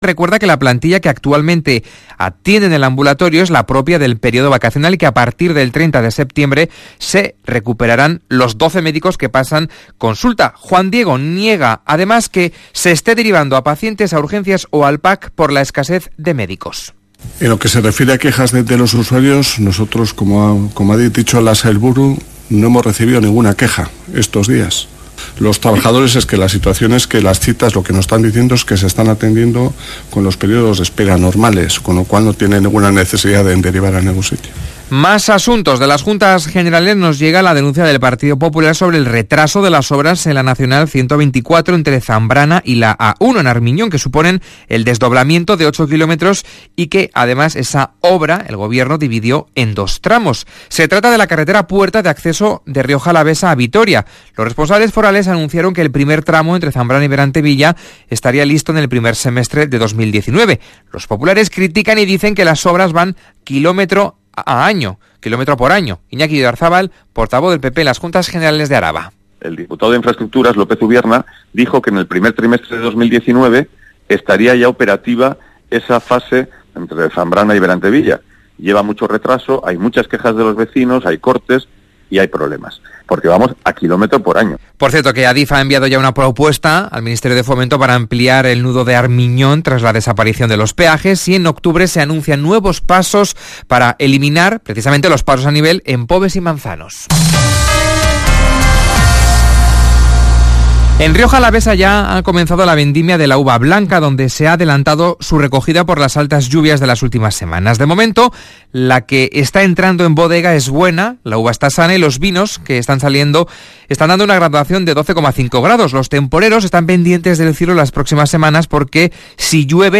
Kirolbet Baskonia-Tenerife Araba saria 2019 retransmisión completa Radio Vitoria